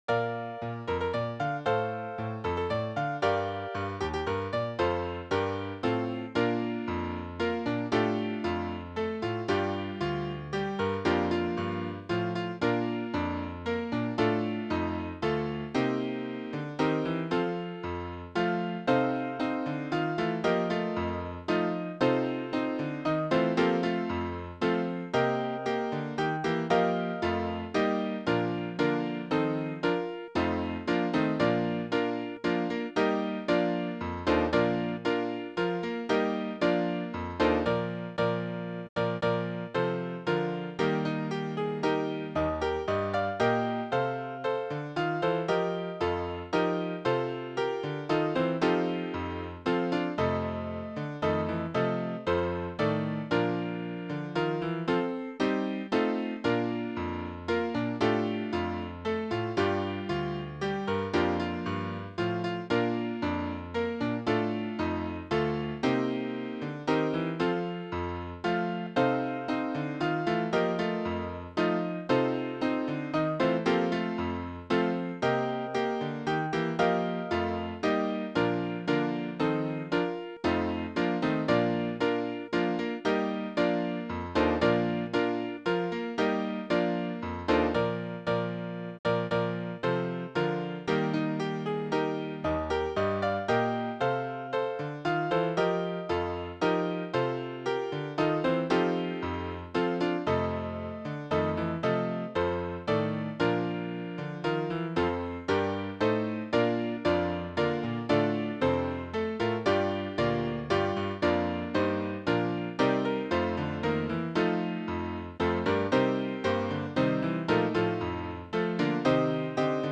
Voicing/Instrumentation: Piano Solo , Vocal Solo